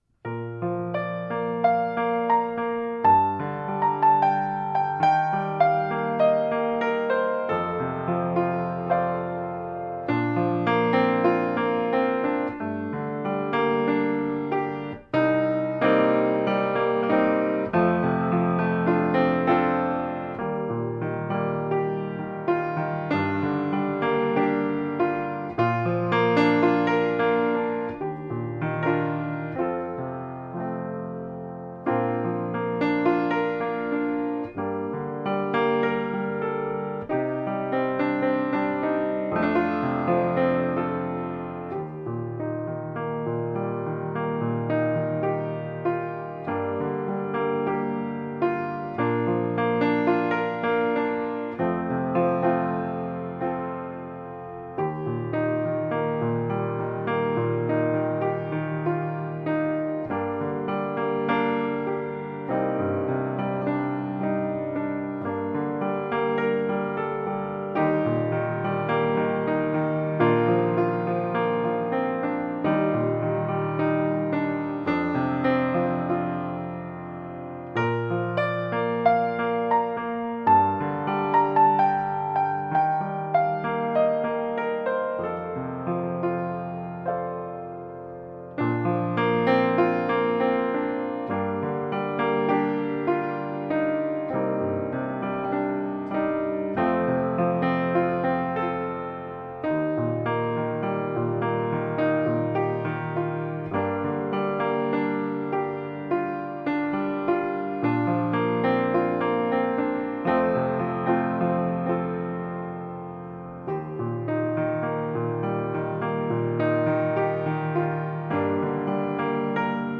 音乐风格：New Age